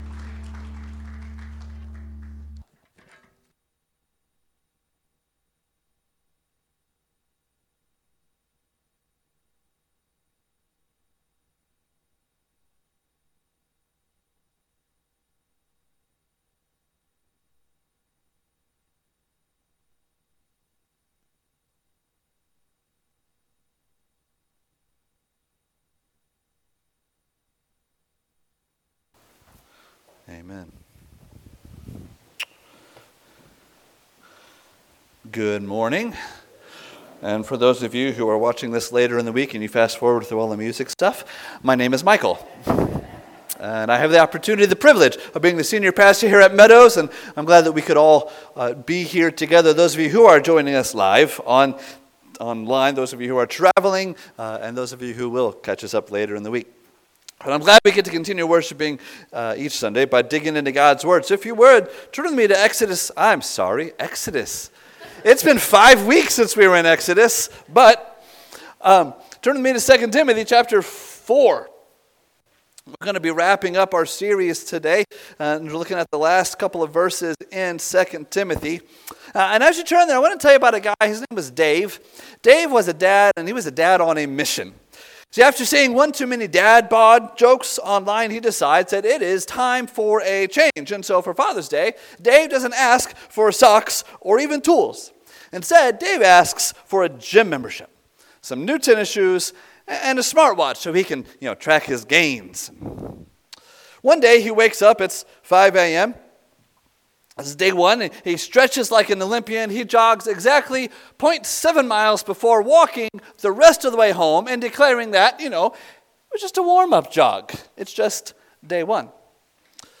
Sermons by Meadows Christian Fellowship